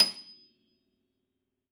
53f-pno27-D6.aif